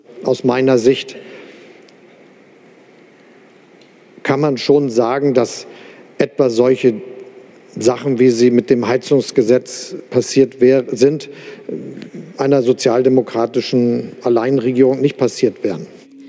stotternden Fehlzündung an seiner leeren Worthülse!
Scholz-stozttern.mp3